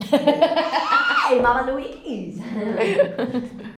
GIRLS FUN.wav